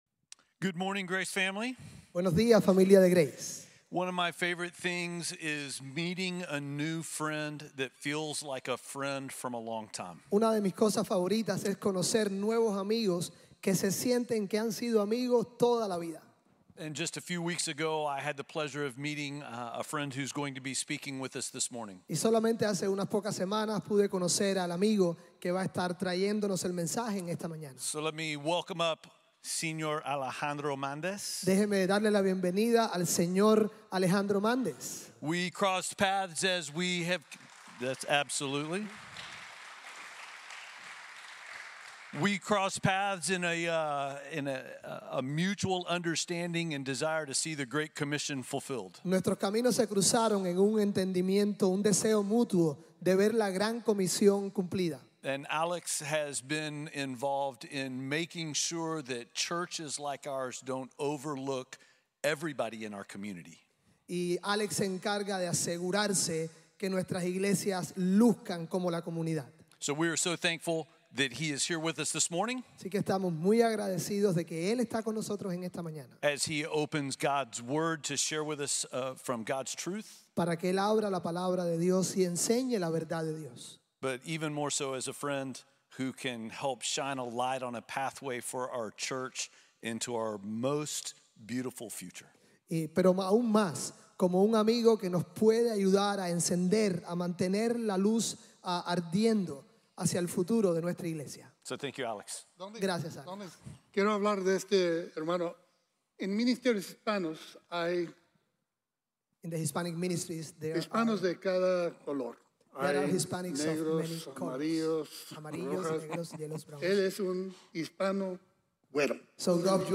Vengan, vean y cosechen | Sermon | Grace Bible Church